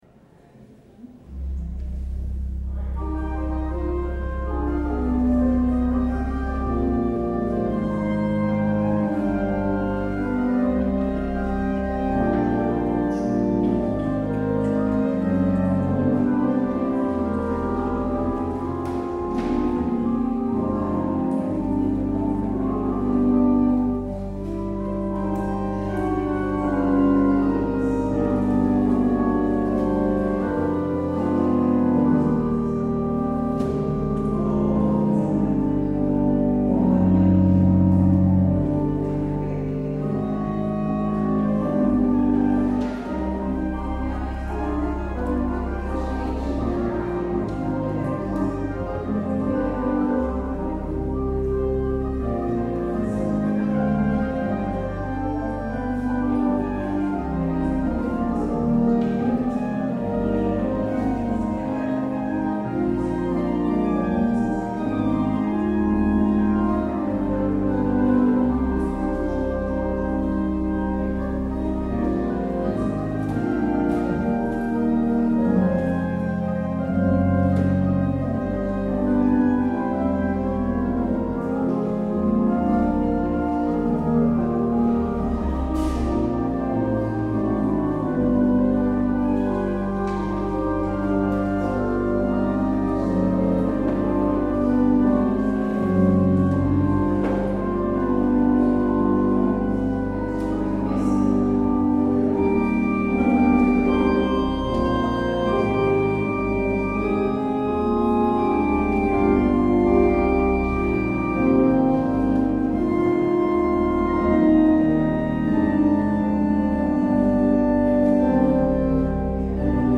kerkdienst